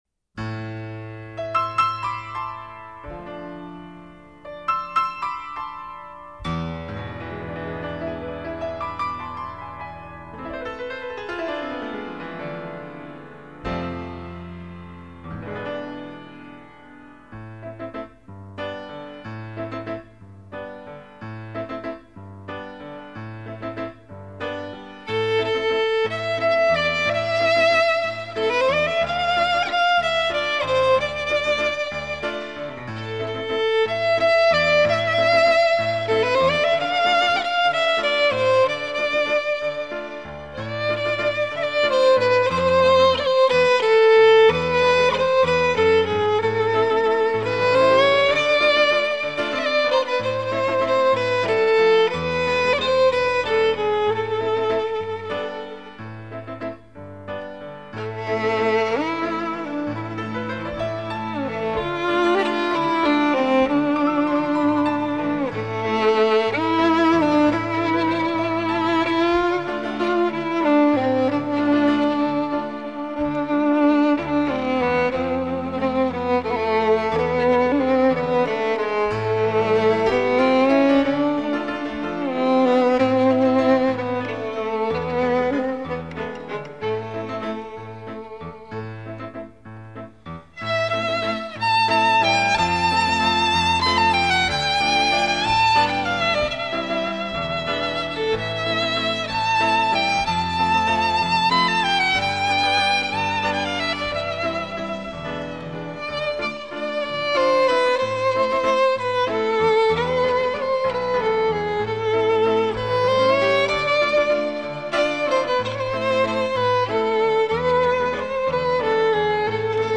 آهنگ بی کلام شاد